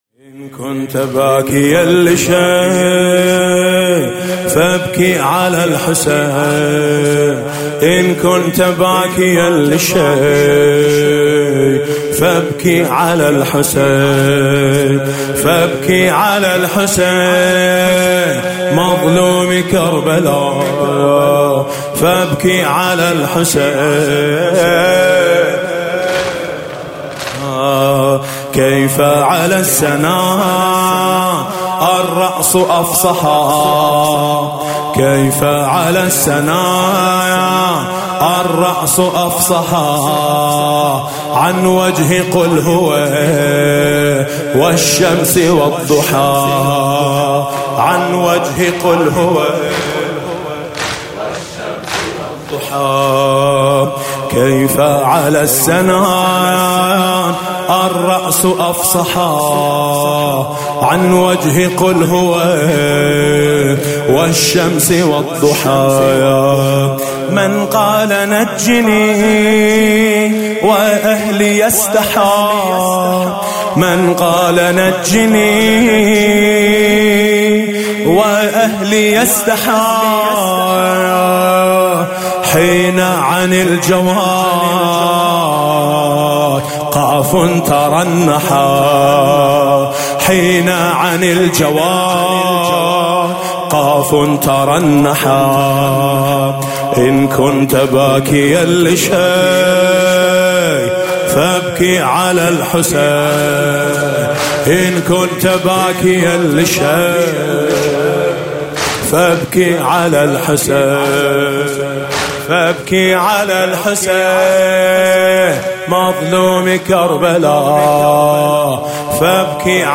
مداحی شب هفتم محرم ۹۹
بخش ۱ : مادرا بچه هاشونو علی اصغر میکنن – روضه
بخش ۶ : من تو رو از روضه‌های خونگی میشناسم – زمینه